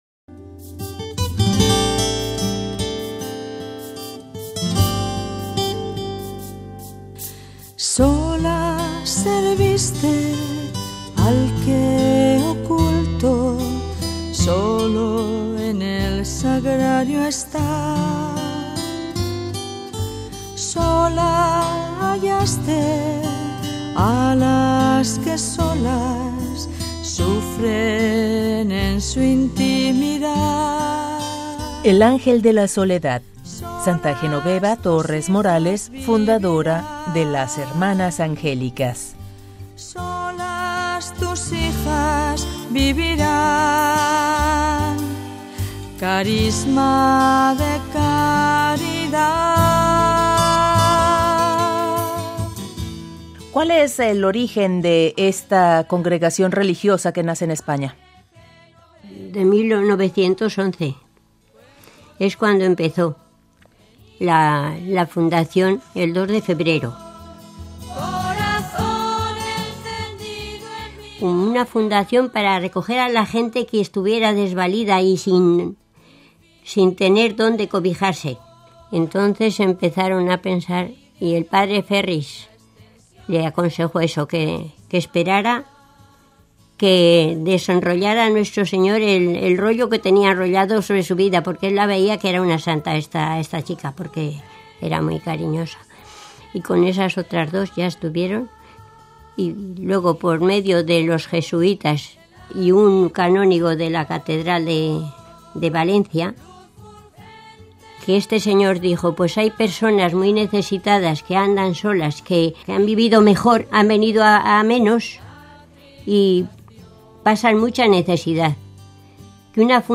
Coros de las Madres Angélicas.